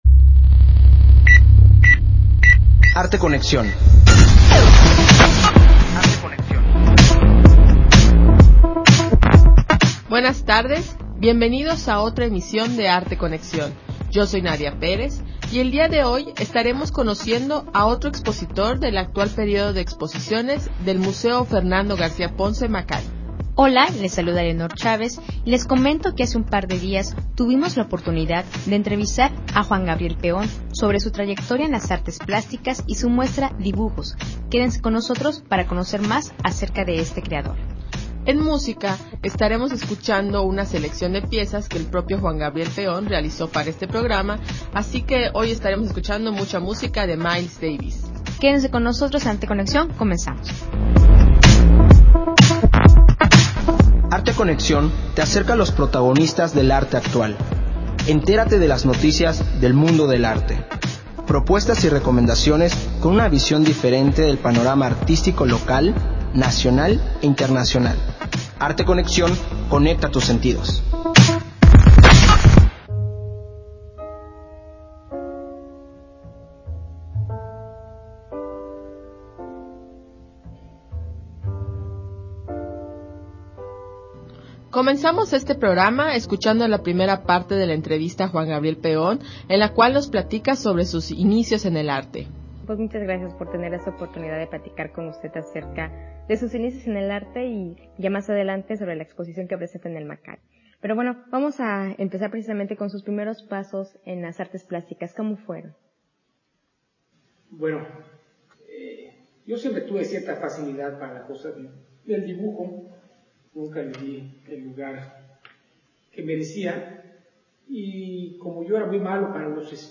Programa de Arte Conexión transmitida el 5 de junio.